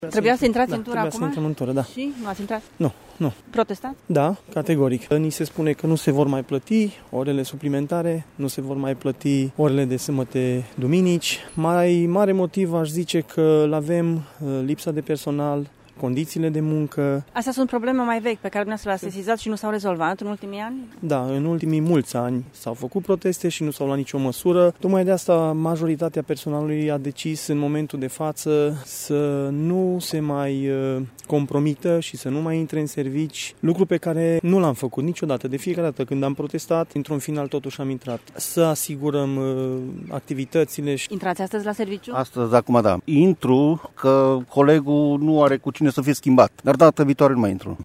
Polițiștii care au refuzat astăzi să intre de serviciu spun că e pentru prima dată când se ia o măsură atât de drastică.